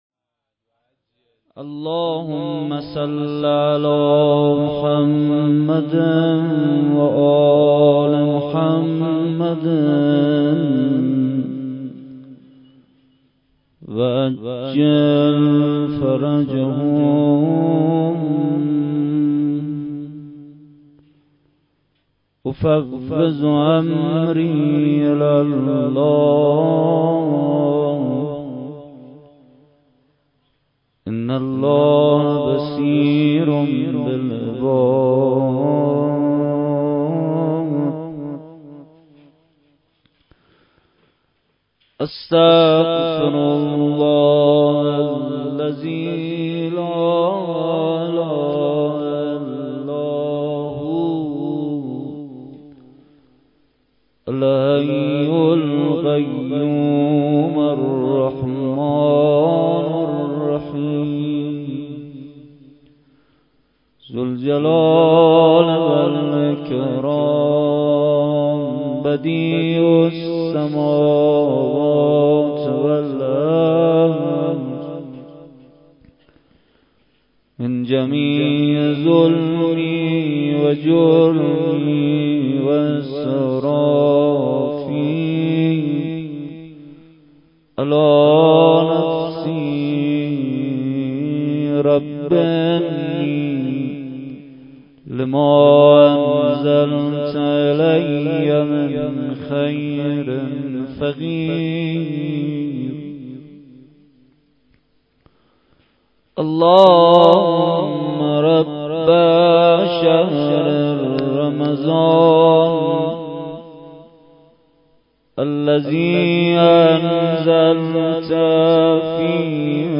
شب هشتم ماه رمضان با مداحی کربلایی محمدحسین پویانفر در ولنجک – بلوار دانشجو – کهف الشهداء برگزار گردید.
بخش اول :مناجات بخش دوم:روضه لینک کپی شد گزارش خطا پسندها 0 اشتراک گذاری فیسبوک سروش واتس‌اپ لینکدین توییتر تلگرام اشتراک گذاری فیسبوک سروش واتس‌اپ لینکدین توییتر تلگرام